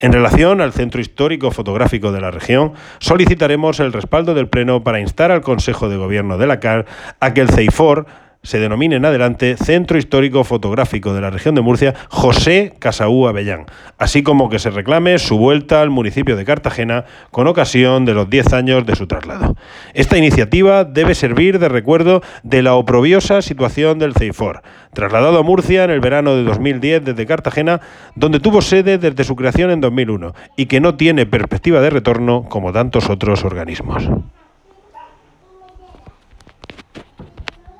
Concejal de MC Cartagena Jesús Giménez